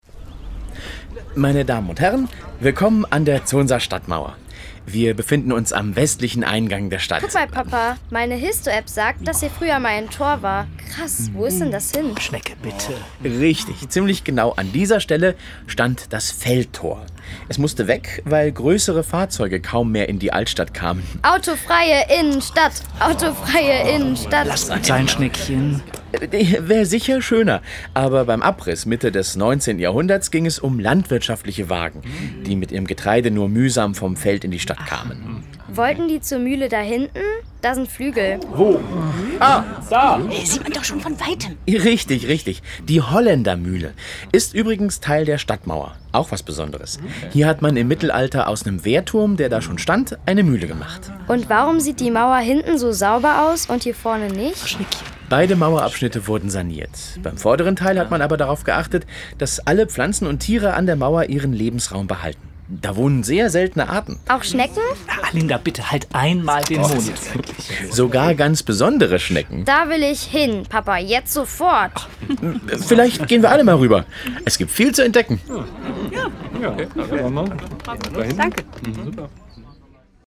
Die Audio-Station bietet acht lustige und unterhaltsame Hörgeschichten für Kinder und Erwachsene.
Hörgeschichten zur Zonser Stadtmauer: